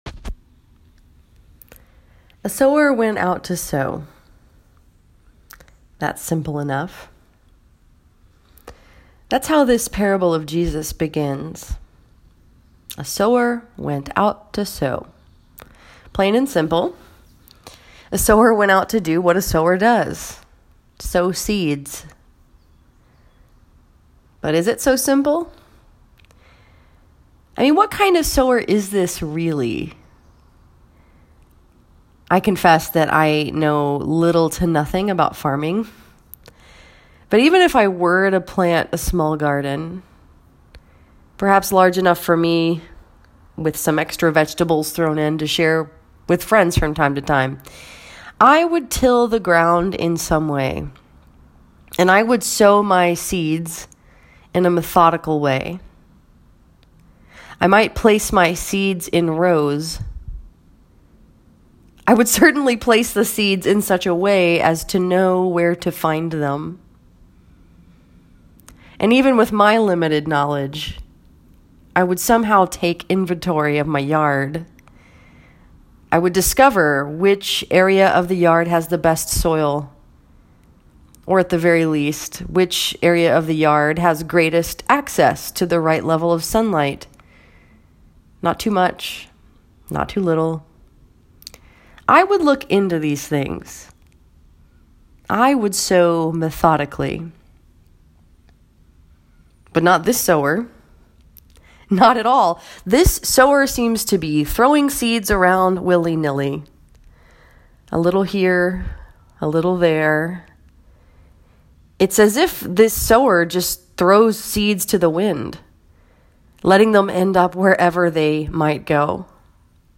This sermon was preached with Northside Presbyterian Church in Ann Arbor and was focused upon Matthew 13:1-9, 18-23.